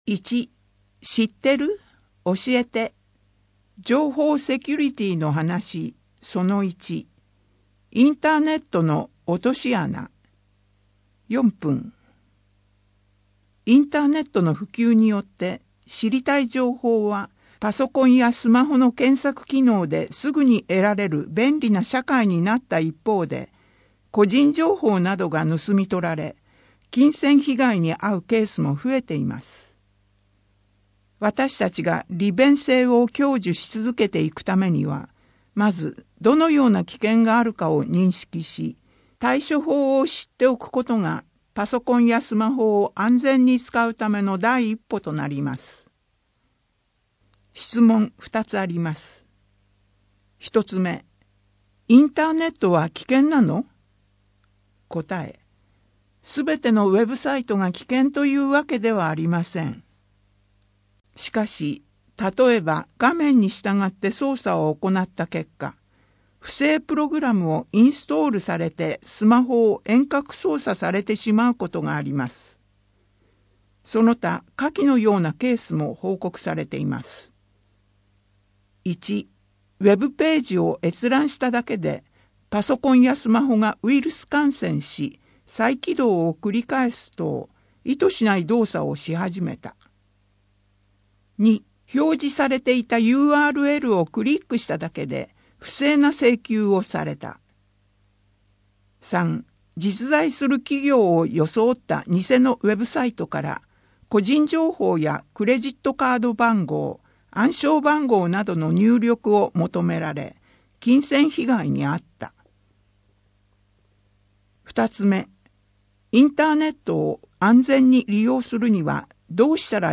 自動通話録音機能付きの電話機などの購入費を一部助成します 音声データ 声の広報は清瀬市公共刊行物音訳機関が制作しています。